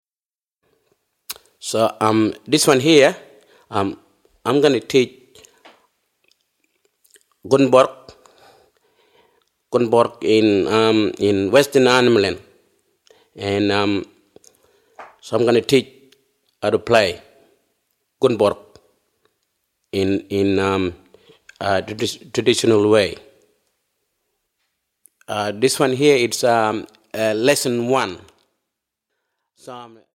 The Mago - This instrument (the didjeridu) is known as Mago in the Mayali language group of Western and Southern Arnhem Land.
Alternative,Indigenous